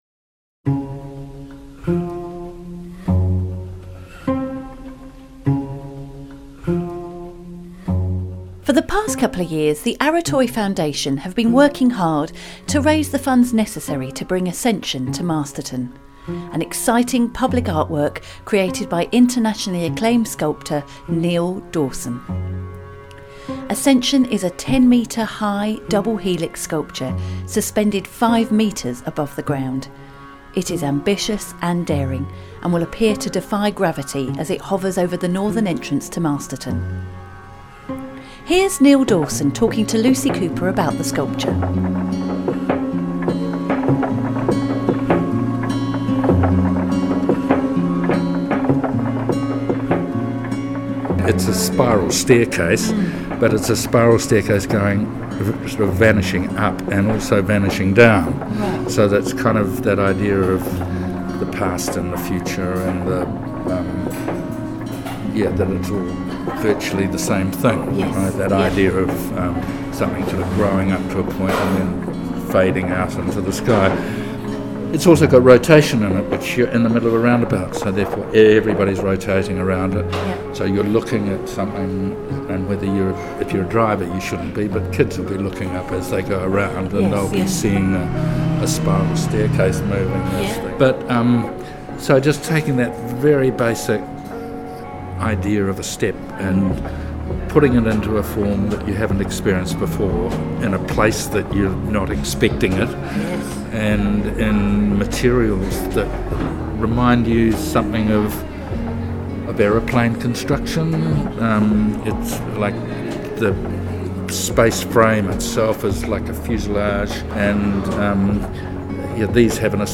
Interview with Neil Dawson